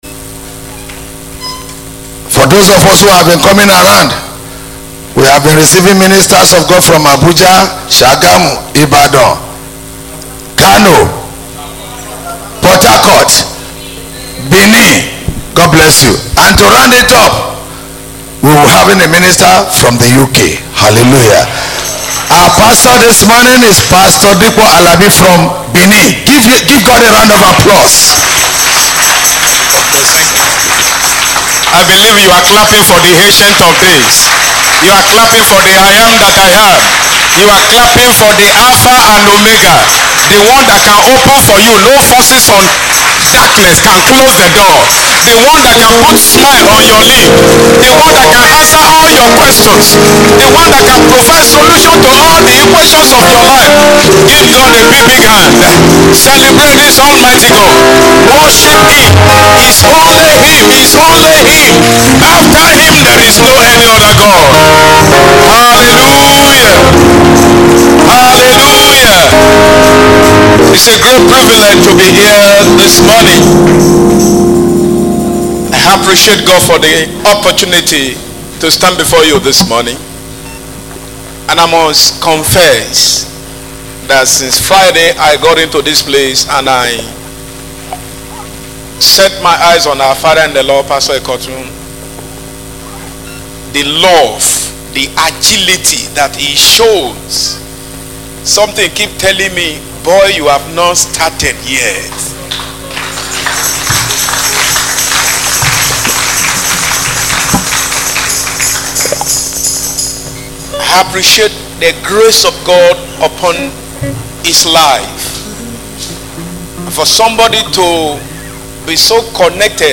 Sermon
Sunday Service